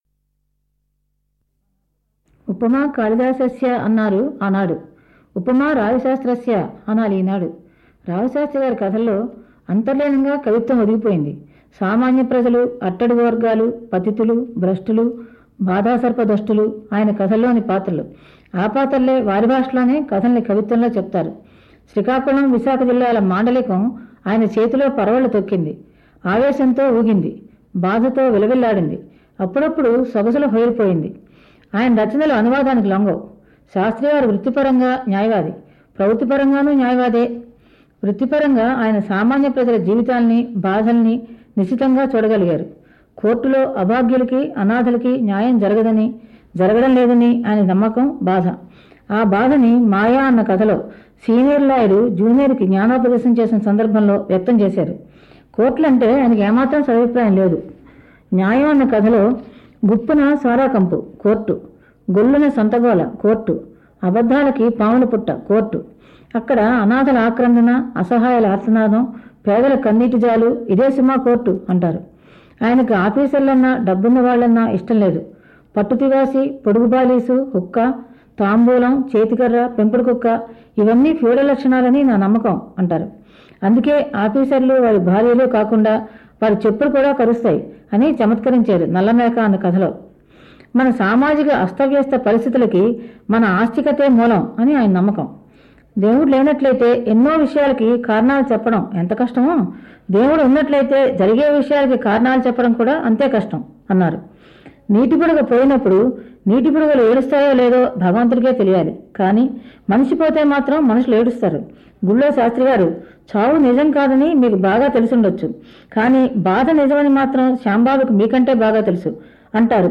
రాచశైలిలో అక్షరాలే ఆయుధాలు – రావిశాస్త్రి రచనలపై బీనాదేవి ప్రసంగం
ఇది భాగవతుల త్రిపుర సుందరమ్మగారు రావిశాస్త్రిగారి కథలపైన 1997లో విజయవాడ కేంద్రంలో చేసిన ప్రసంగం.
beenaadEvi_raaviSaastri-kathalu.mp3